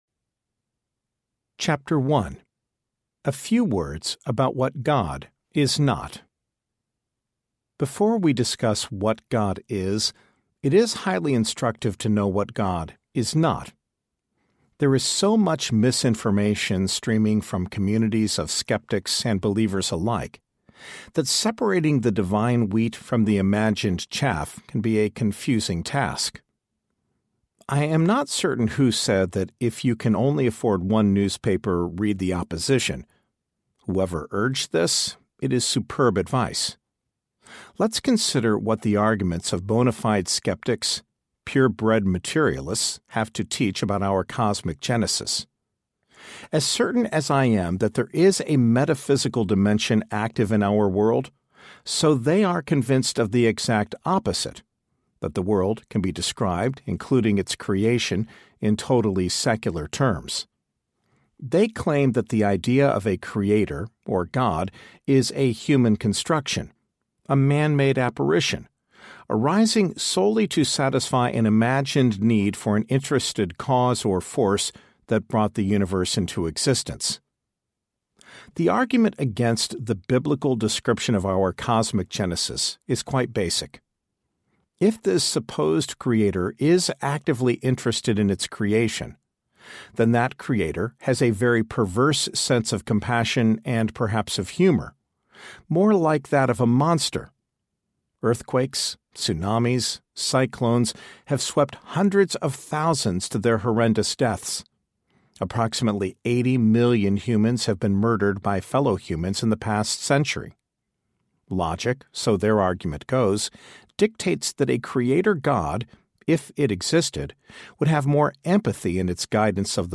God According to God Audiobook
8.5 Hrs. – Unabridged